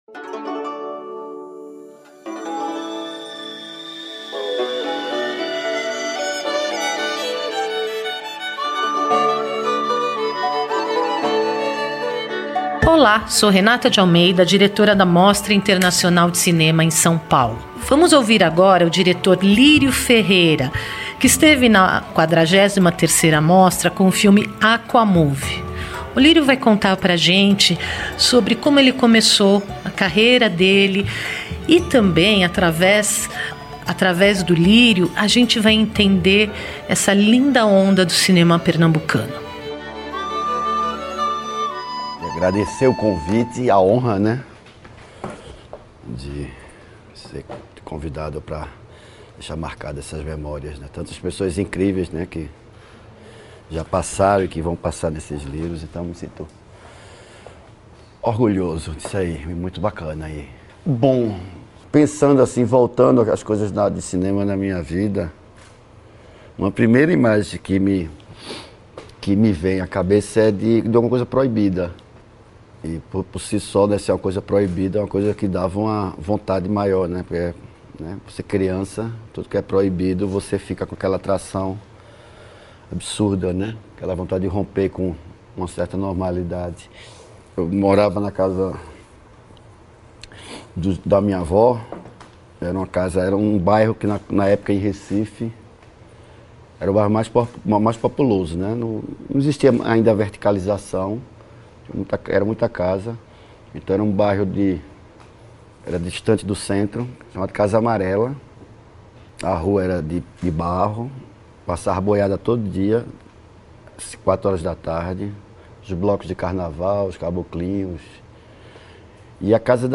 Film Interviews